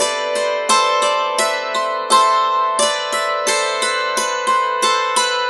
Dulcimer05_87_G.wav